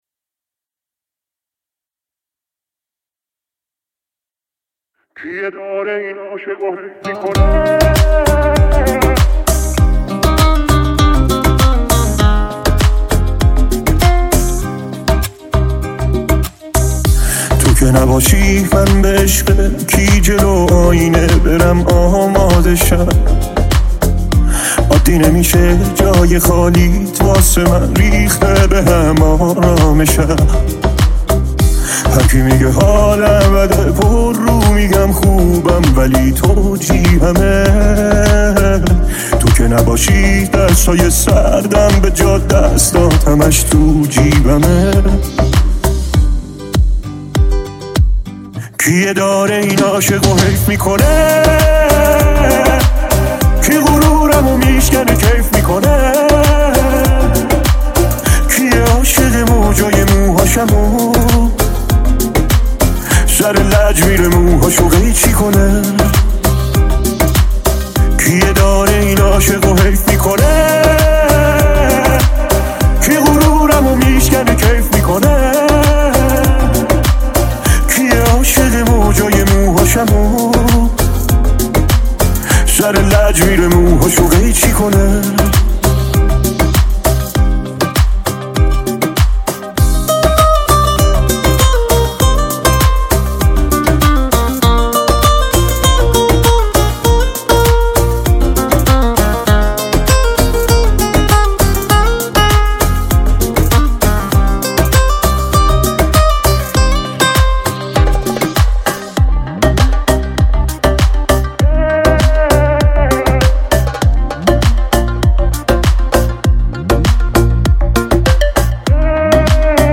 پاپ ریمیکس رقص عاشقانه